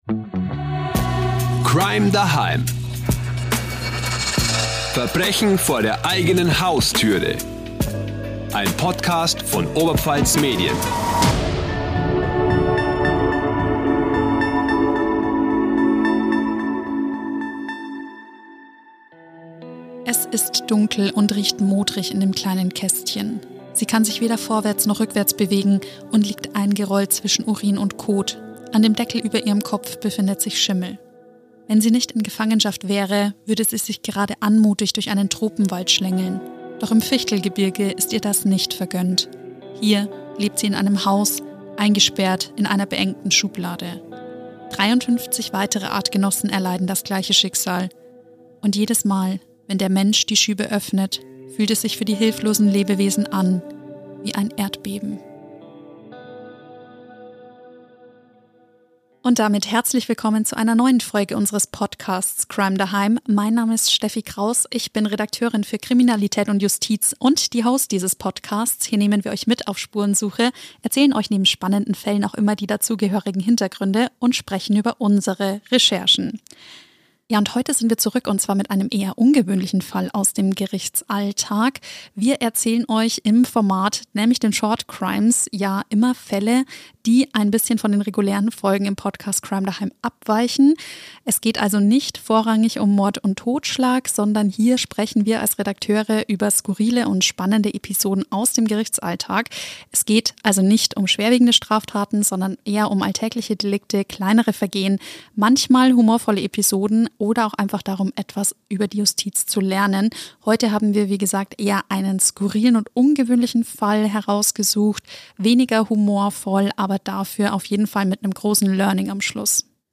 Der True-Crime-Podcast von Oberpfalz-Medien